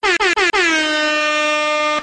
Air Horn 3 Sound Effect Free Download
Air Horn 3